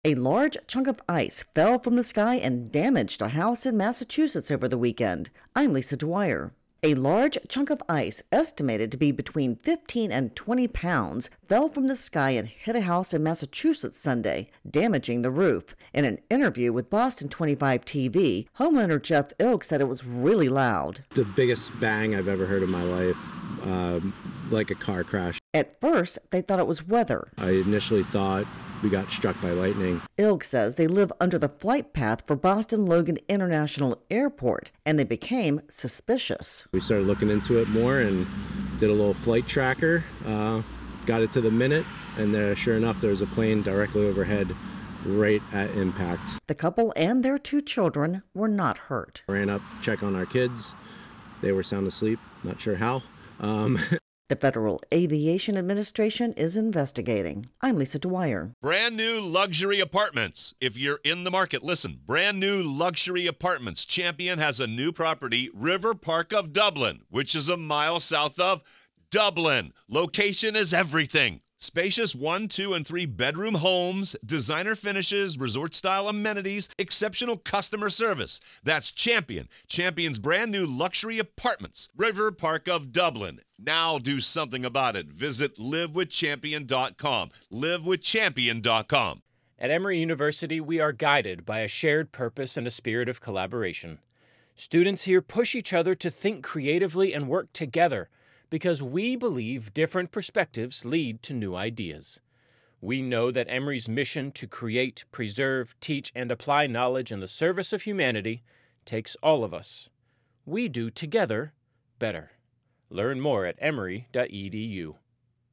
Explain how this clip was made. SOUND COURTESY BOSTON 25 TV ((included in package - mandatory on-air credit))